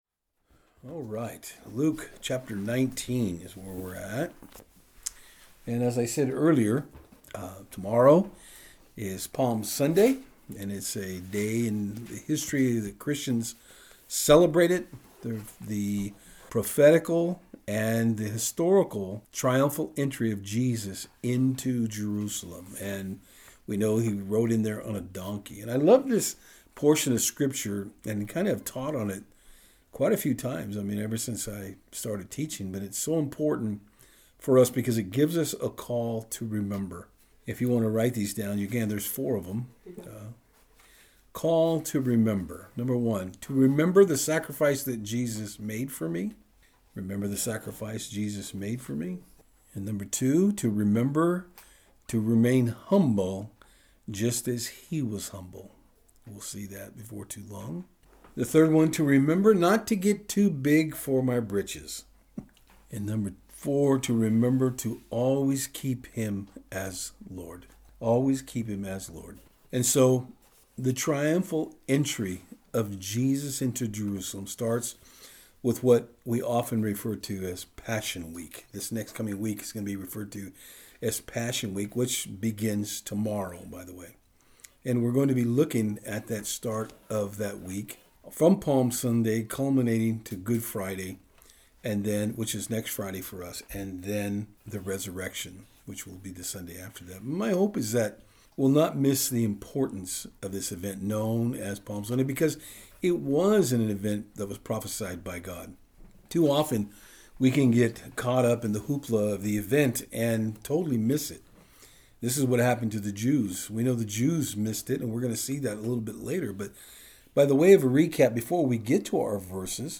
Palm Sunday Message